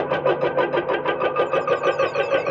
RI_DelayStack_95-02.wav